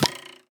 ArrowHit.wav